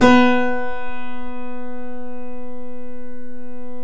Index of /90_sSampleCDs/Roland L-CD701/KEY_Steinway ff/KEY_Steinway M